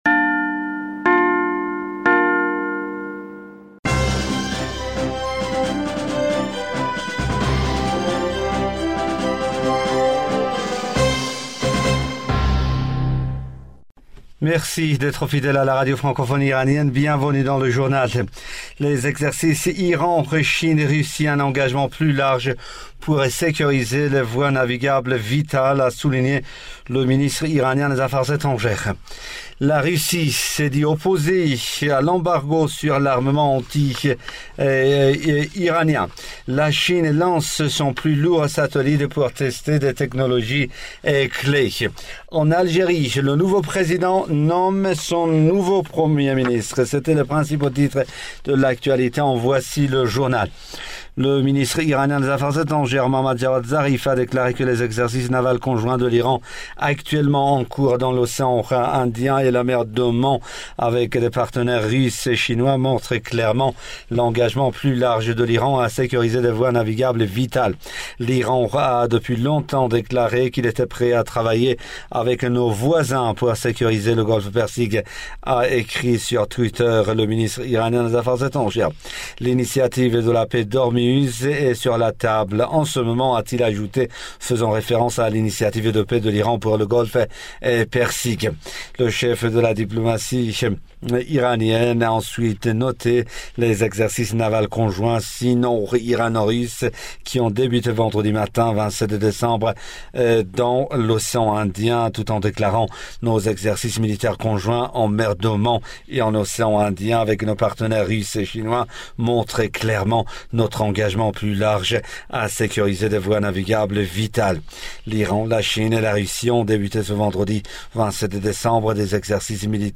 Bulletin d'information du 28 decembre